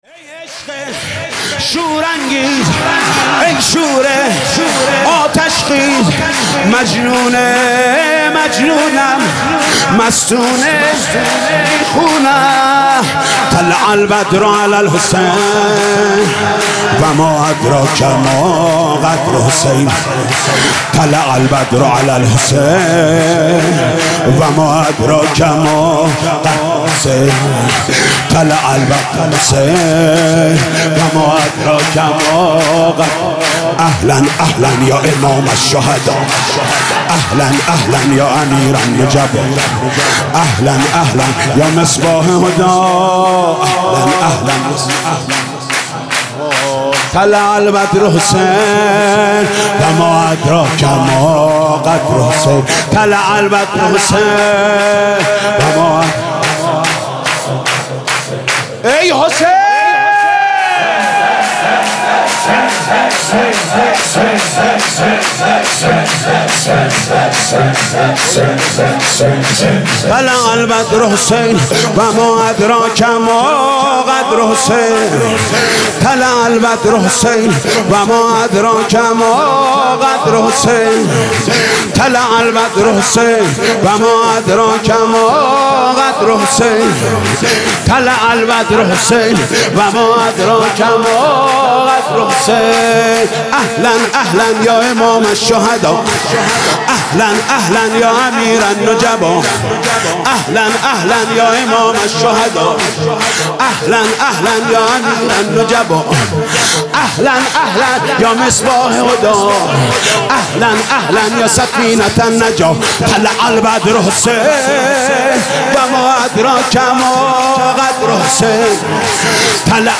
سرود: ای عشق شور انگیز